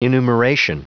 Prononciation du mot enumeration en anglais (fichier audio)
Prononciation du mot : enumeration